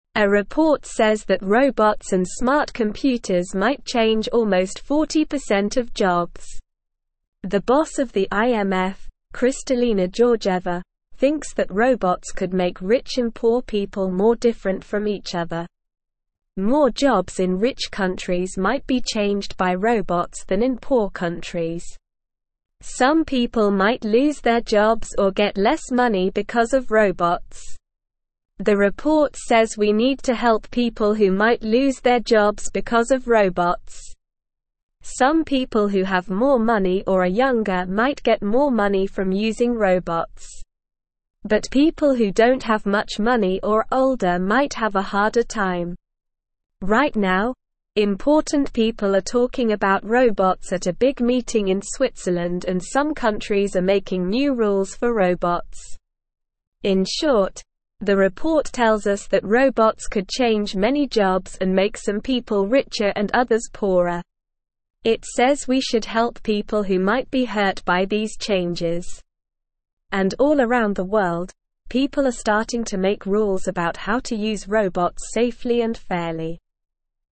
Slow
English-Newsroom-Lower-Intermediate-SLOW-Reading-Smart-computer-programs-could-change-many-jobs.mp3